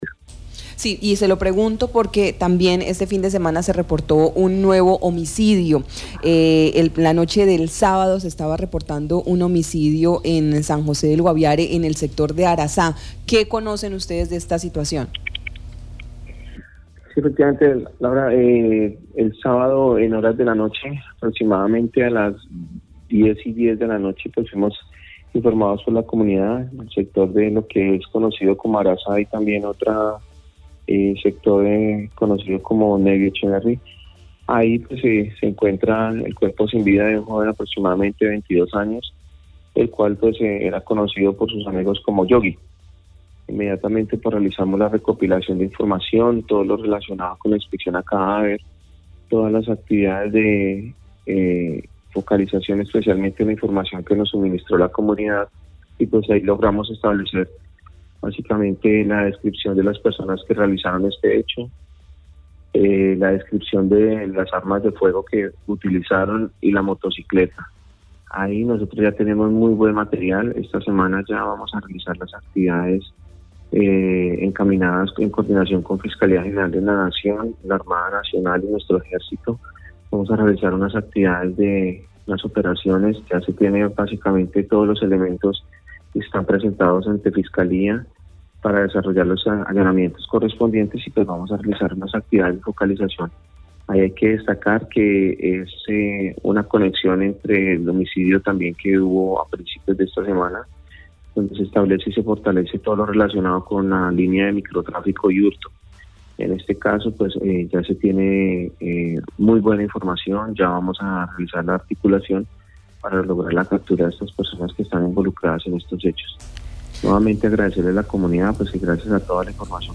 El coronel Ángel Alexander Galvis Ballén, comandante Departamento de Policía Guaviare, indicó que la institución tiene importante información de la descripción de los responsables, armas de fuego y de la motocicleta.